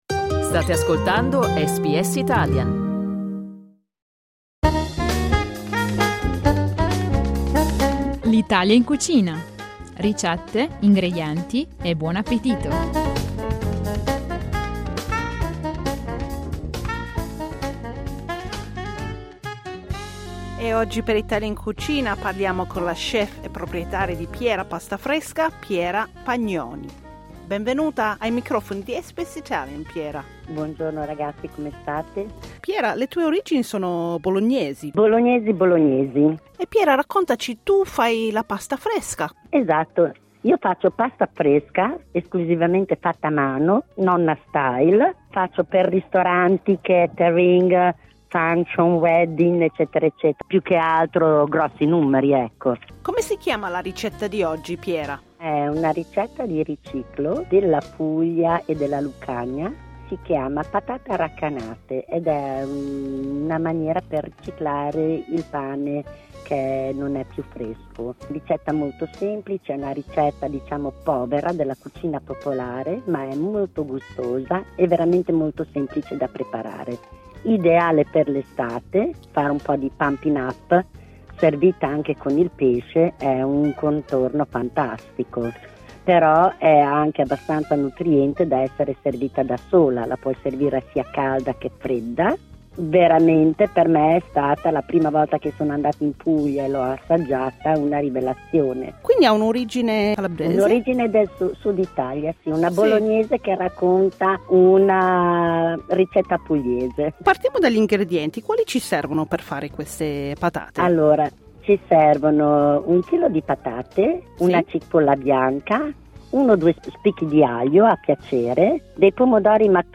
Clicca sul tasto "play" in alto per ascoltare l'audioricetta